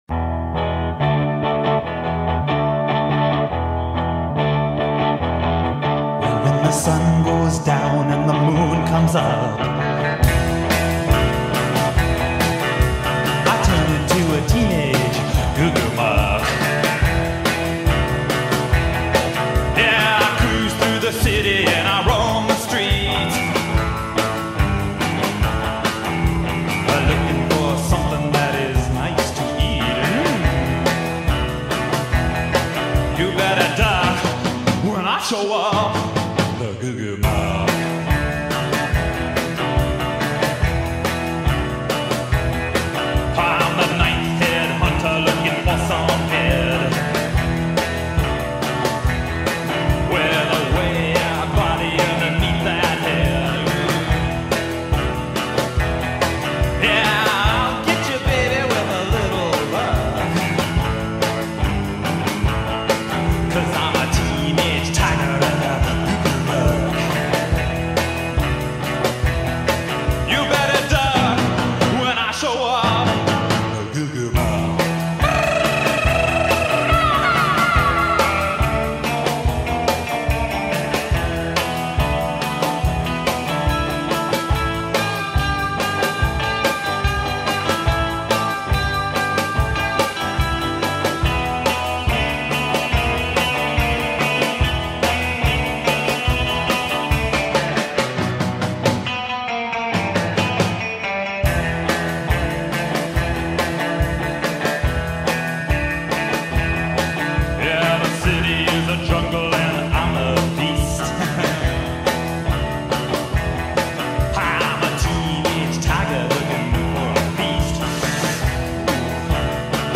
американской рок-группы